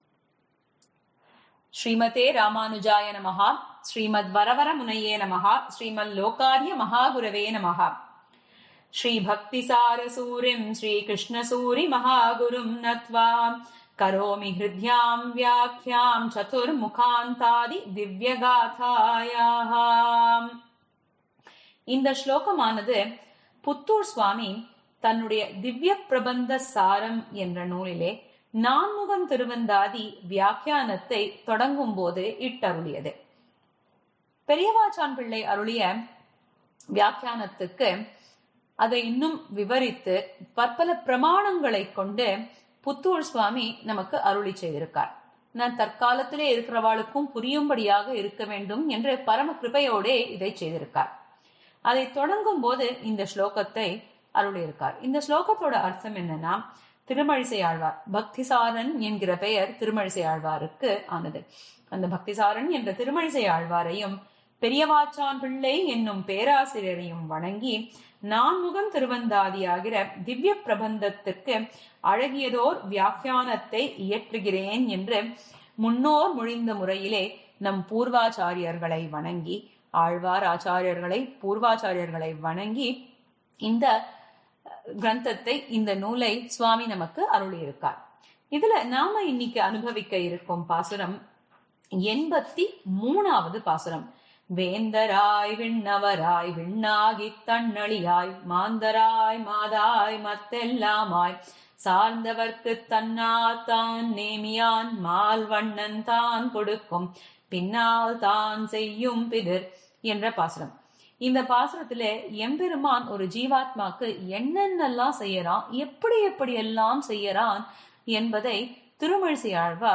ஸ்ரீ புத்தூர் ஸ்வாமி தை மாத ஸதமான விழாவின் அங்கமாக,
ஆறெனக்கு நின் பாதமே சரண் அளிக்கும் உபந்யாசத் தொடரில் –